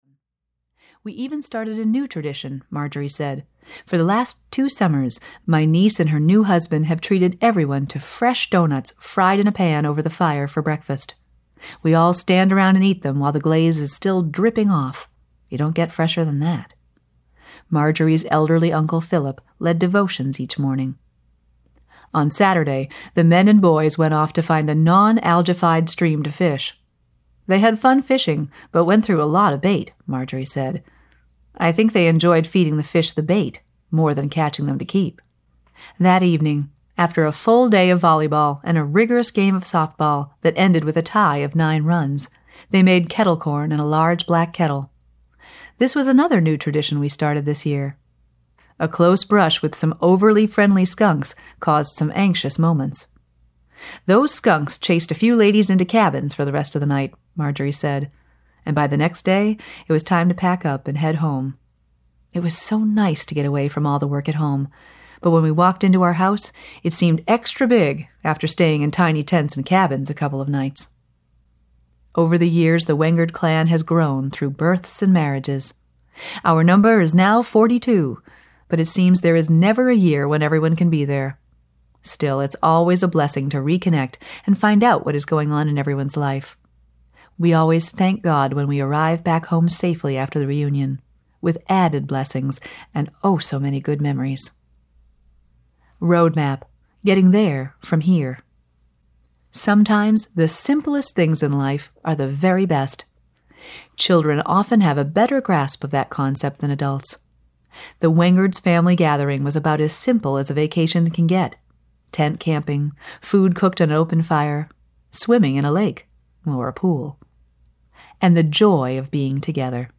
Amish Values for Your Family Audiobook
Narrator
4.4 Hrs. – Unabridged